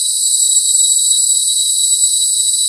rr3-assets/files/.depot/audio/sfx/forced_induction/spool_02.wav